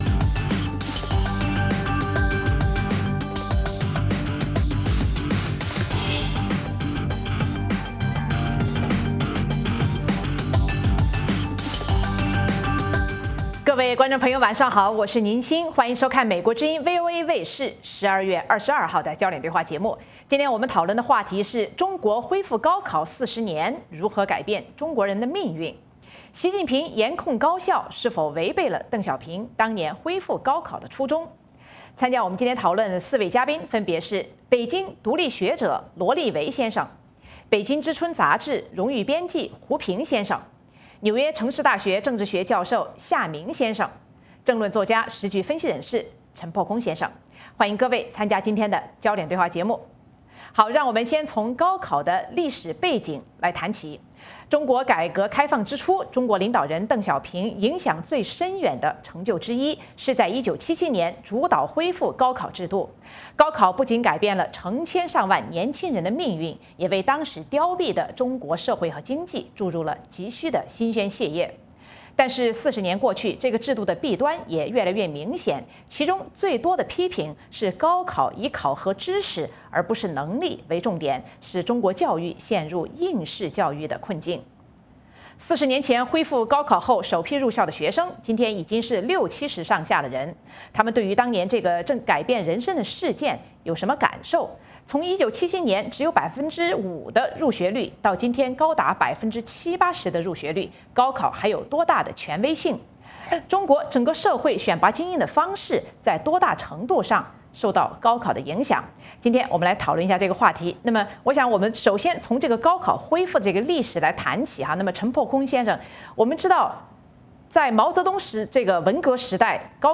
美国之音中文广播于北京时间每周五晚上9-10点播出《焦点对话》节目。《焦点对话》节目追踪国际大事、聚焦时事热点。邀请多位嘉宾对新闻事件进行分析、解读和评论。